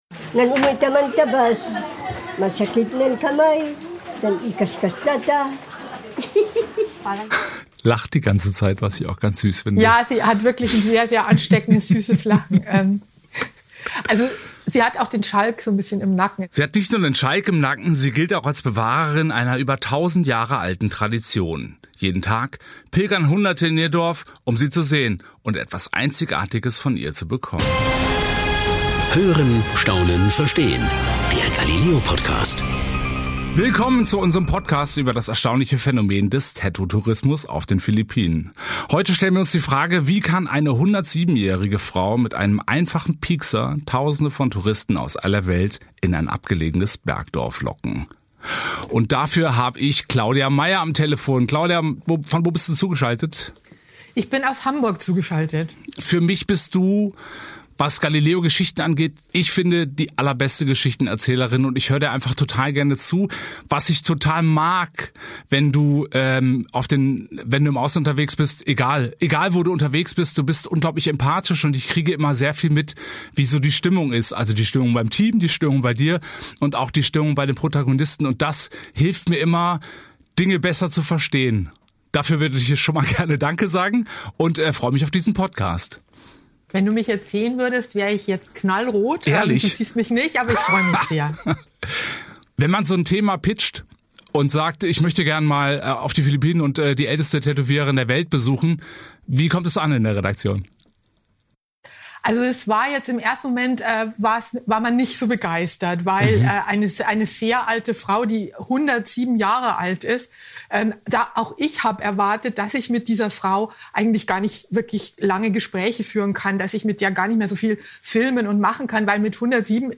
Ein Talk über FlipFlop-Taxifahrer, heilige Tätowiererinnen und die Frage, was wirklich unter die Haut geht.